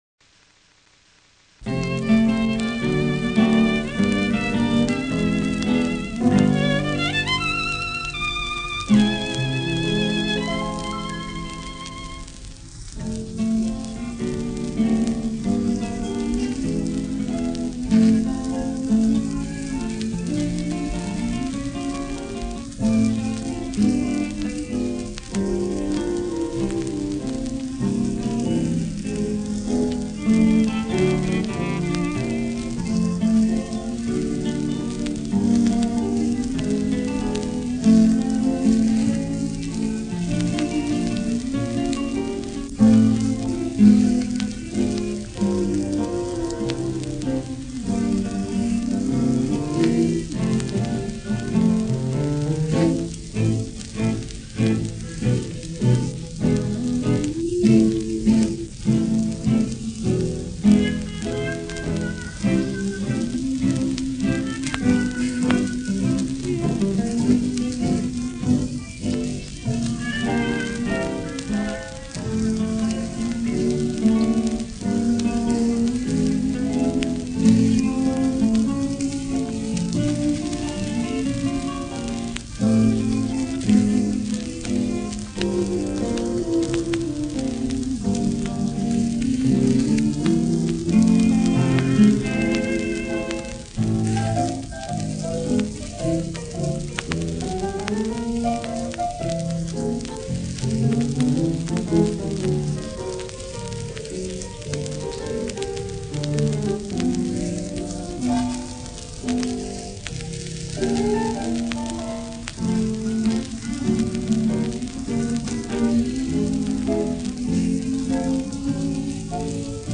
I could hear the guitar so much more clearly.